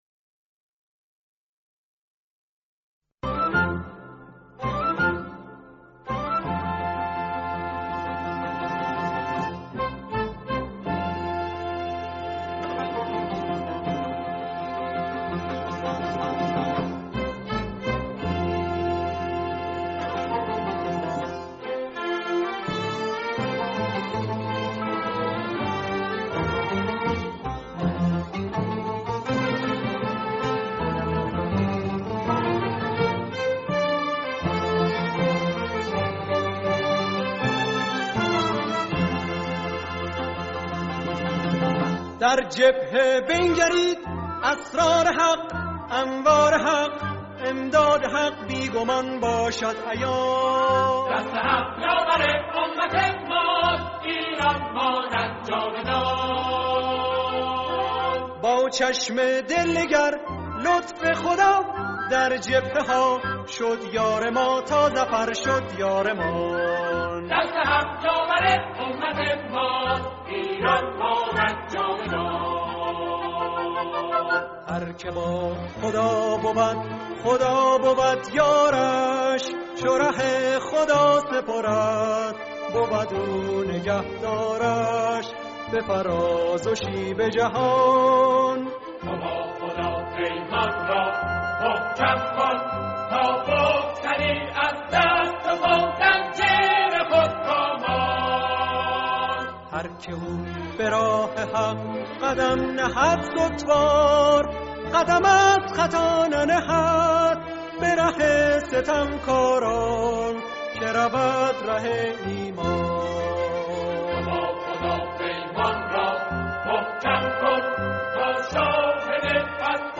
سرود با مضمون دفاع مقدس، خواننده
گروه کر